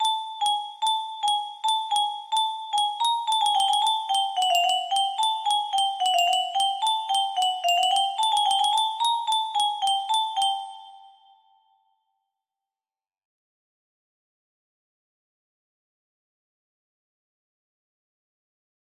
Test lullaby music box melody